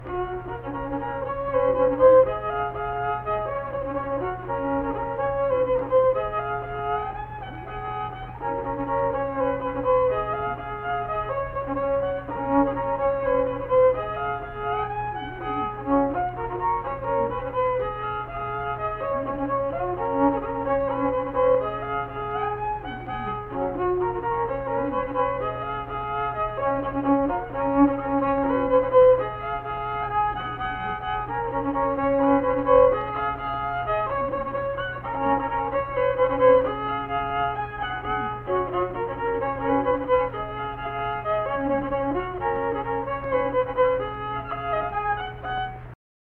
Unaccompanied fiddle music performance
Instrumental Music
Fiddle